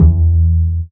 Upright Bass.wav